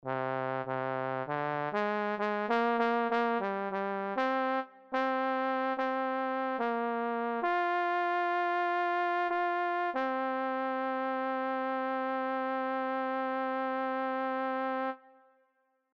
Key written in: C Minor
Type: Barbershop
Each recording below is single part only.